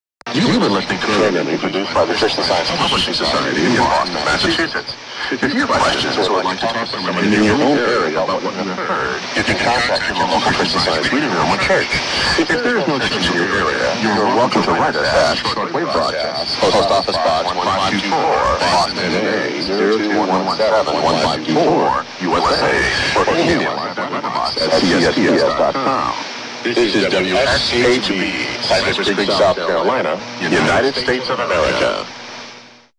WSHB Dual Path Echoes (MP3) (2000)
This is the best example of dual-path echo I've ever recorded, in a Sunday broadcast of Christian Science Radio.
The powerful transmitter is beaming away from California, and conditions are so good that signals come both the short way, about 2000 miles in this case, and the long way, pretty much clear around the world and delayed by the trip. Path losses perfectly offset antenna gain, and it's usually a tossup as to which echo is going to win.